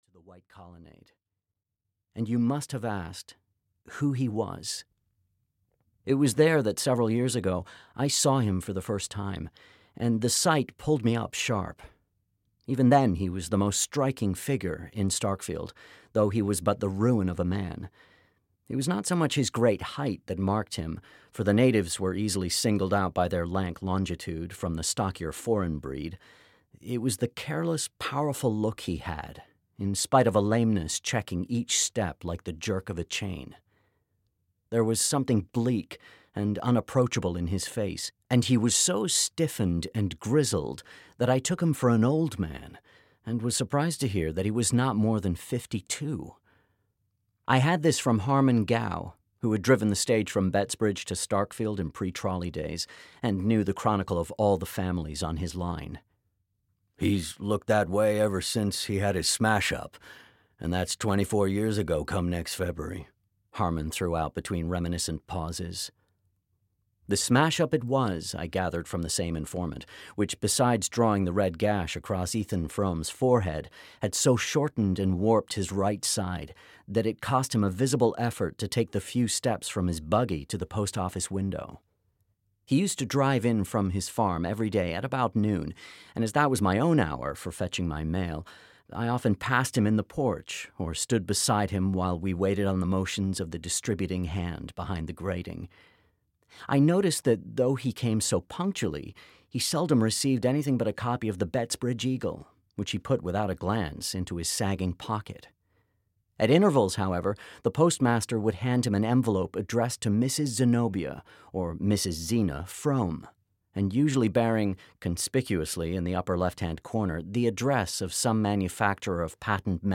Ethan Frome (EN) audiokniha
Ukázka z knihy